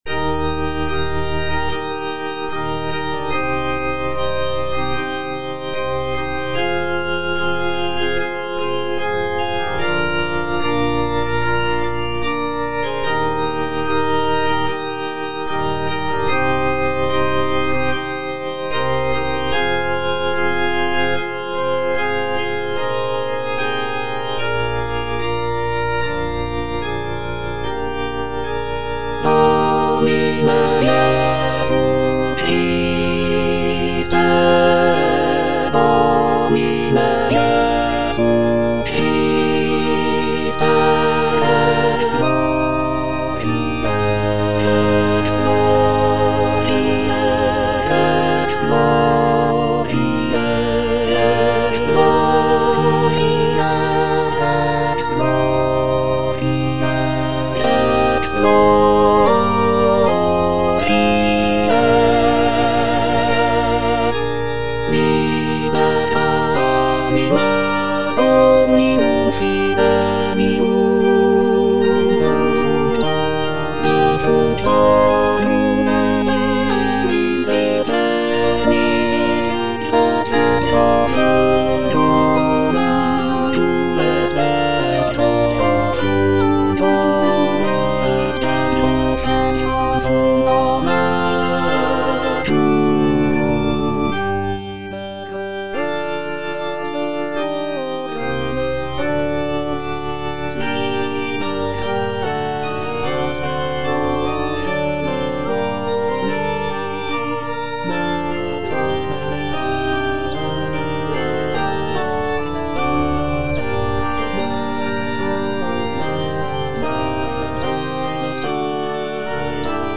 All voices
is a sacred choral work rooted in his Christian faith.